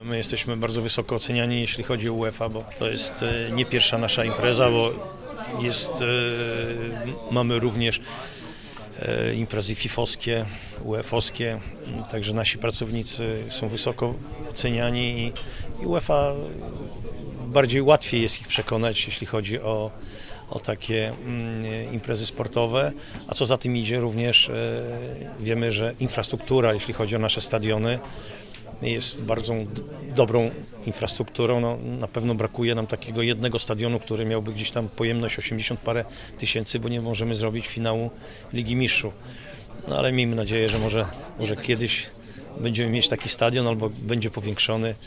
Jak udało się ściągnąć tak dużą imprezę do Polski mówi Cezary Kulesza – prezes Polskiego Związku Piłki Nożnej.